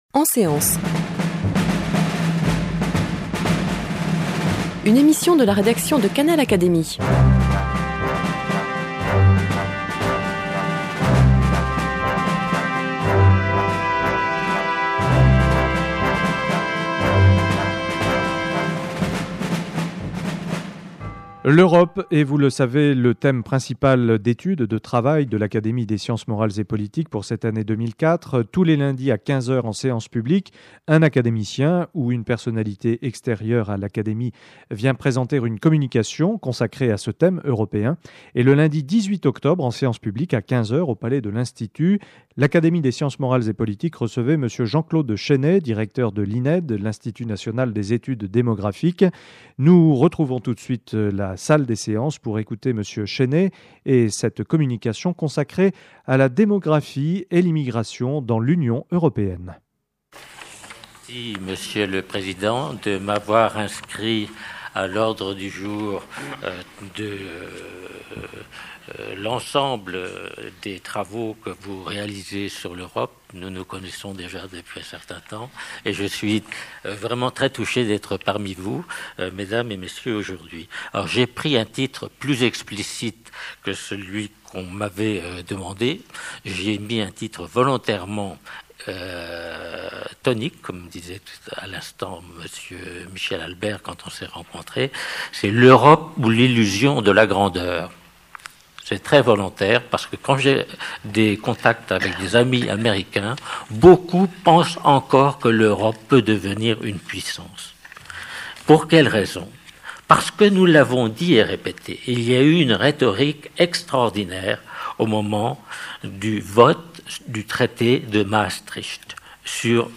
prononcée en séance publique devant l’Académie des sciences morales et politiques le lundi 18 octobre 2004.